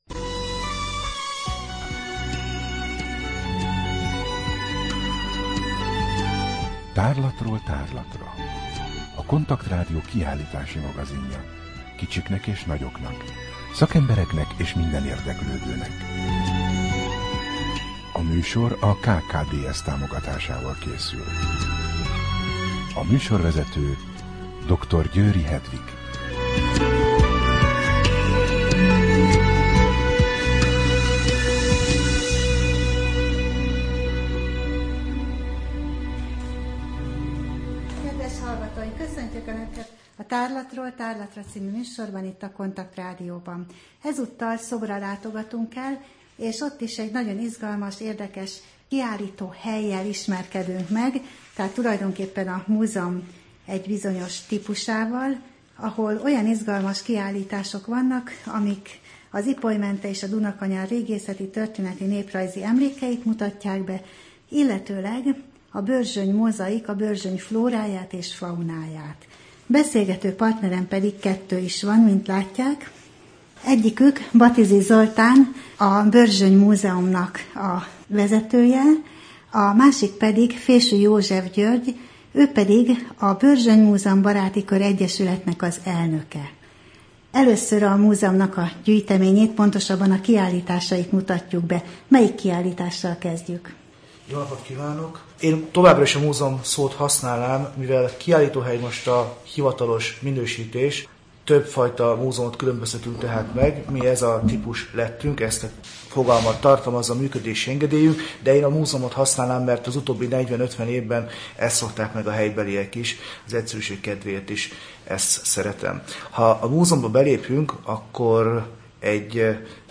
Rádió: Tárlatról tárlatra Adás dátuma: 2013, Június 10 Tárlatról tárlatra / KONTAKT Rádió (87,6 MHz) 2013 június 10. A műsor felépítése: I. Kaleidoszkóp / kiállítási hírek II. Bemutatjuk / Szob, Börzsöny Múzeum A műsor vendégei